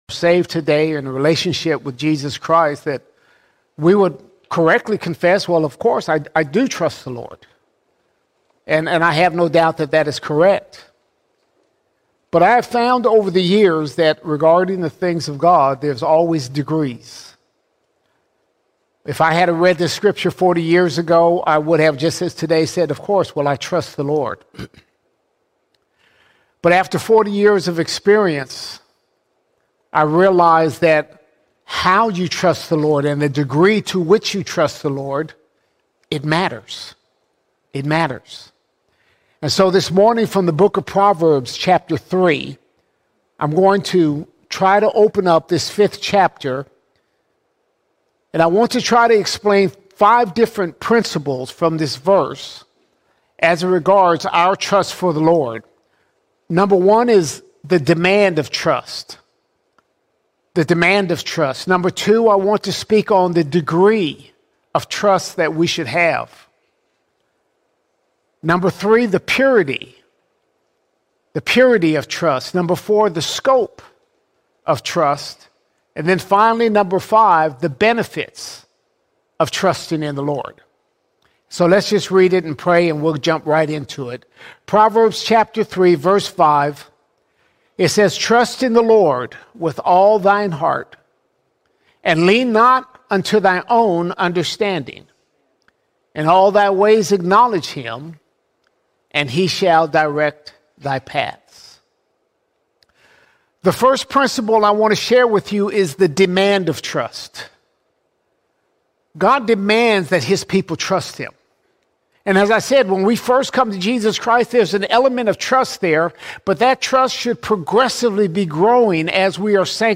1 September 2025 Series: Sunday Sermons All Sermons How To Trust God How To Trust God Proverbs 3:5–6 teaches us that trust in God grows in degrees.